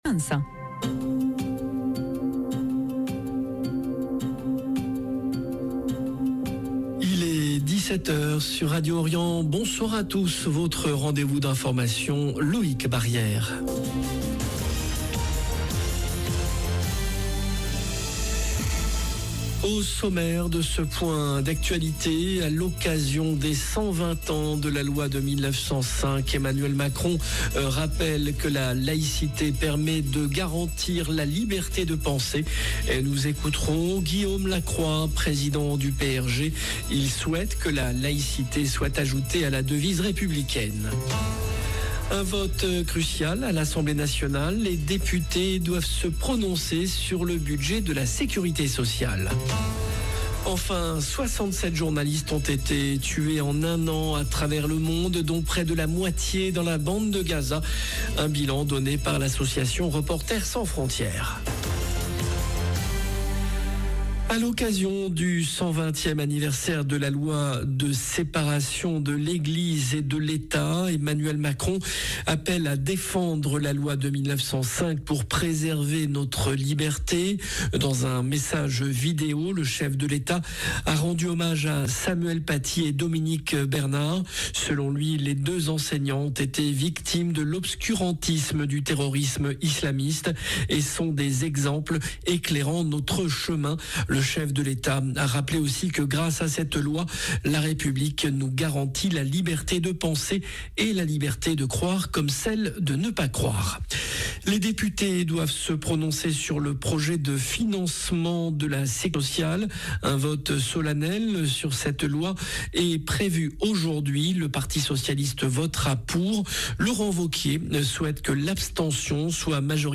JOURNAL DE 17H
Nous écouterons Guillaume Lacroix, président du PRG.